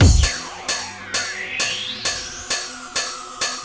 NoisyPercLoop-44S.wav